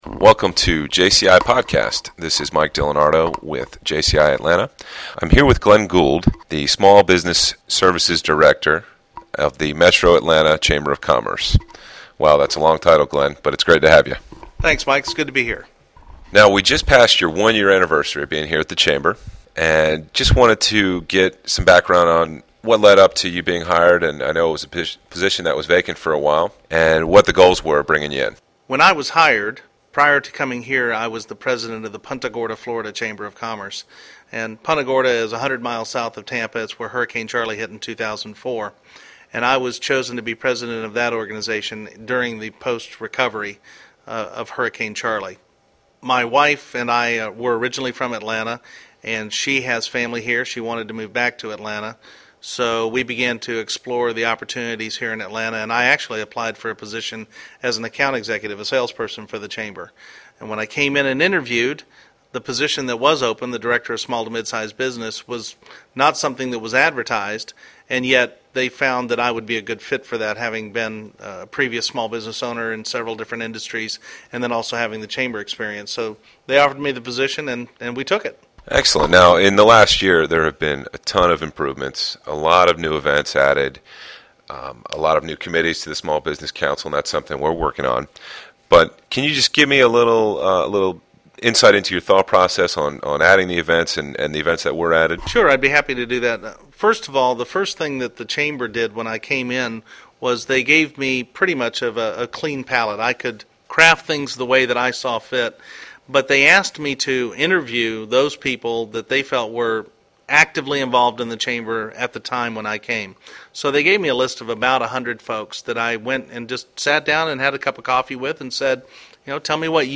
In this interview, we cover what is going on for Small to Mid-size Businesses at the Chamber.